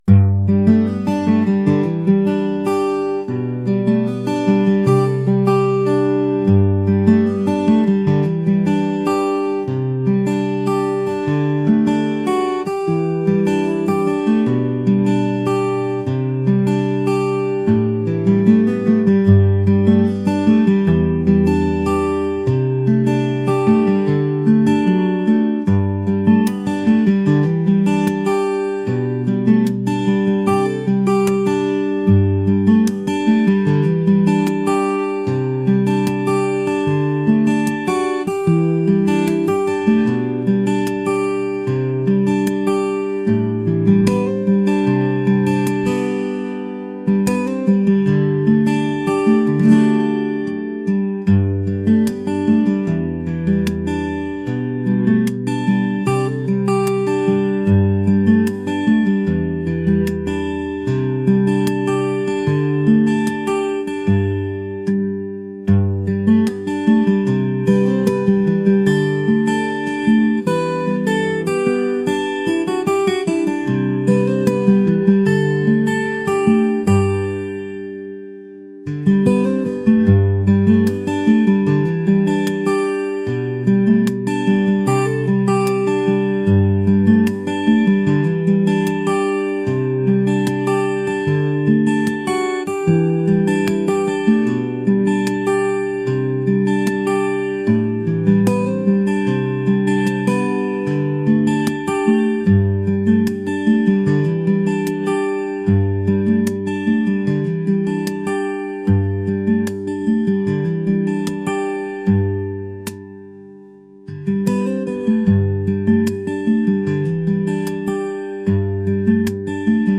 folk | acoustic